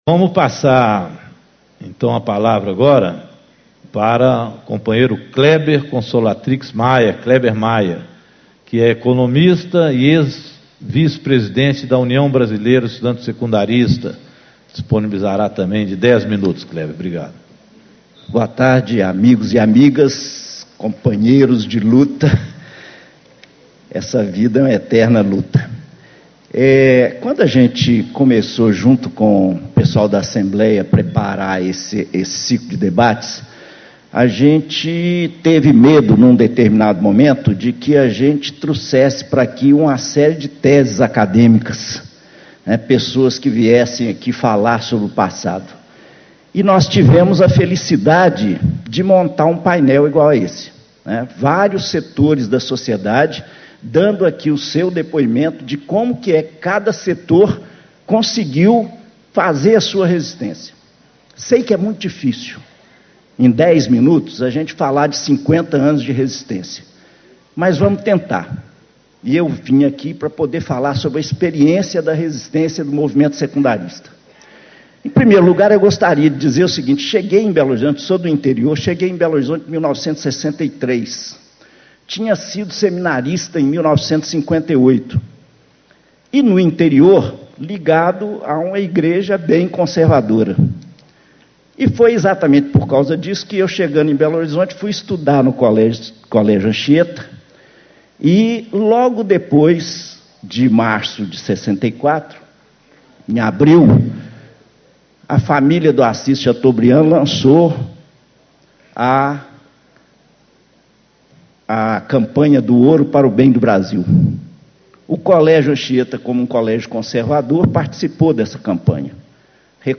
Ciclo de Debates 50 Anos do Golpe Militar de 1964
Discursos e Palestras